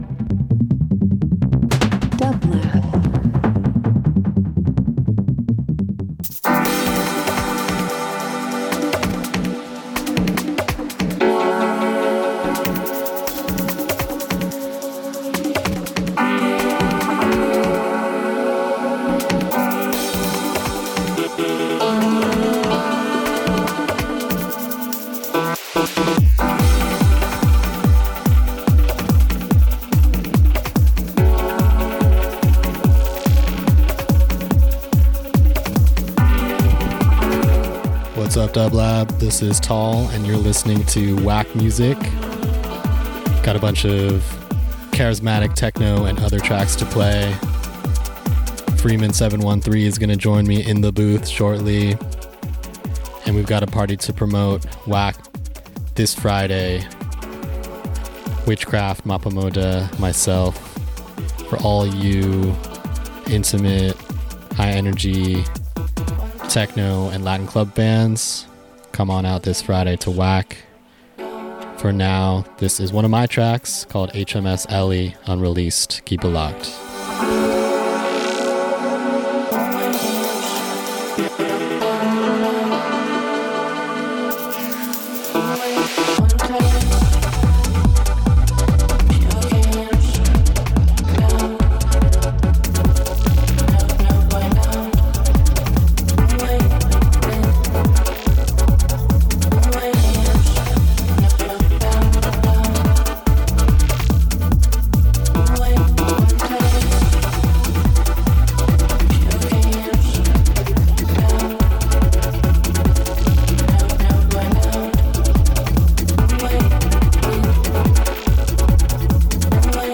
Beats Electronic House Interview Techno